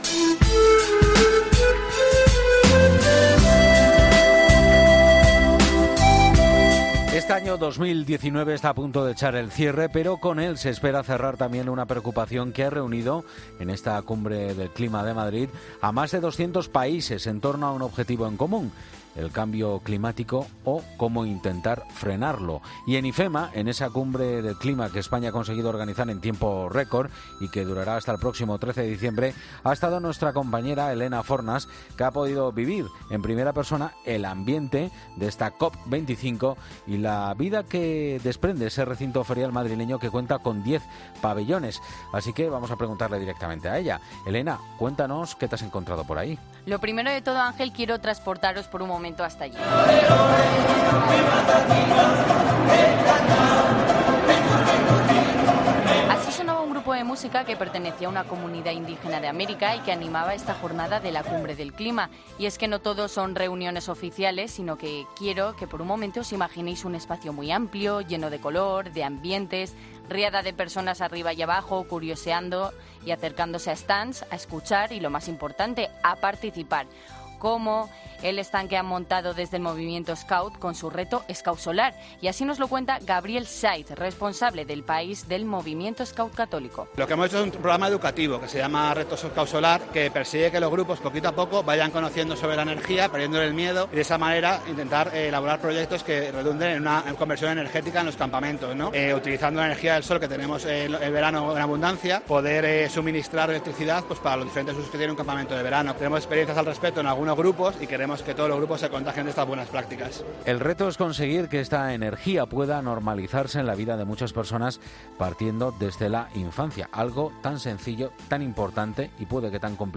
Desde IFEMA hemos podido vivir en primera persona el ambiente y la vida que desprende ese recinto ferial.
Estas son algunas de las voces que dan vida a proyectos de concienciacion y de lucha.